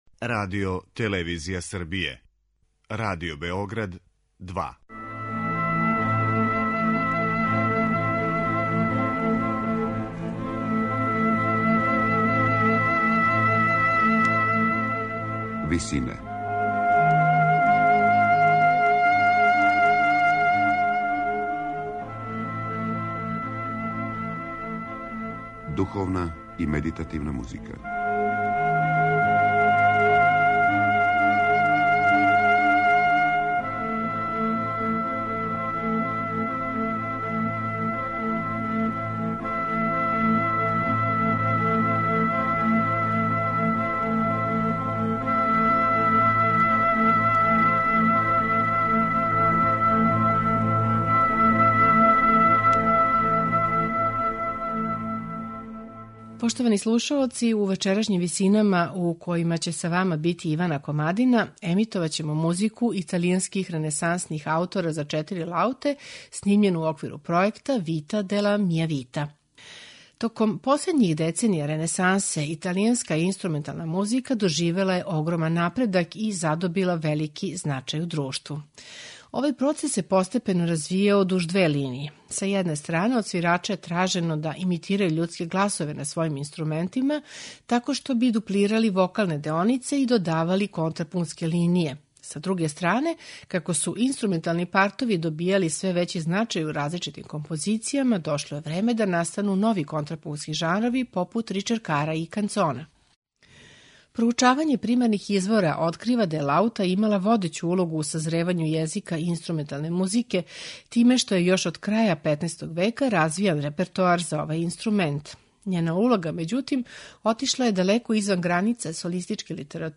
композиције италијанских ренесансних аутора за четири лауте